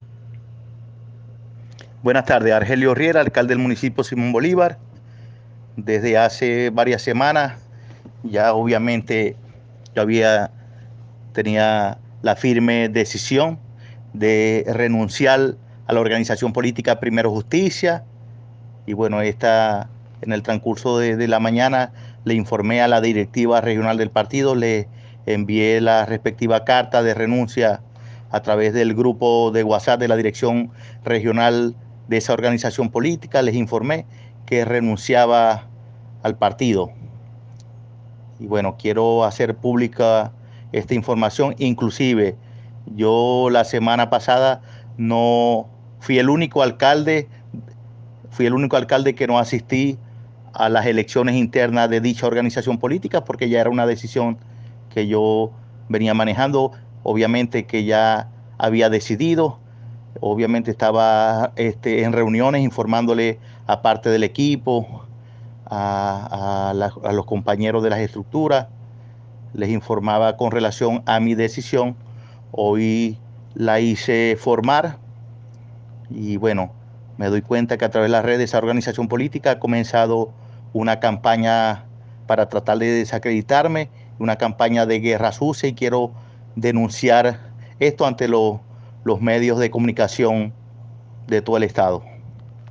En un audio divulgado por las redes sociales, el alcalde de Simón Bolívar del Zulia, Argelio Riera, habló sobre la medida de Primero Justicia de separarlo de las filas de la tolda política.